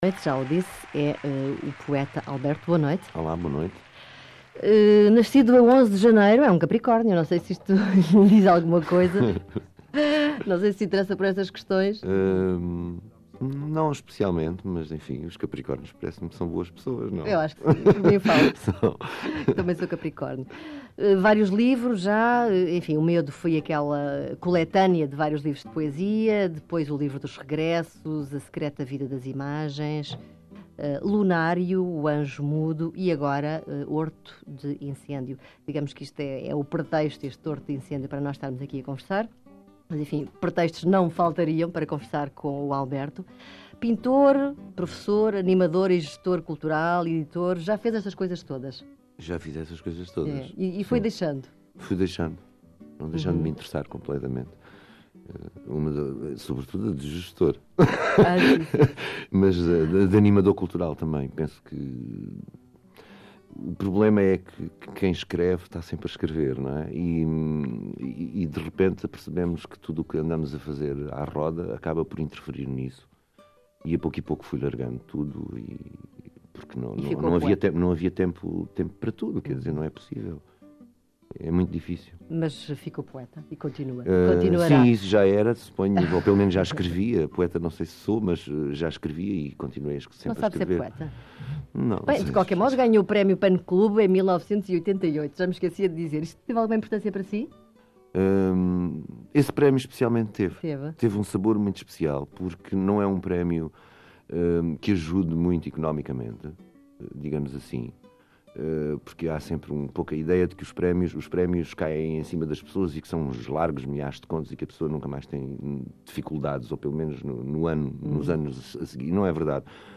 Entrevista a Al Berto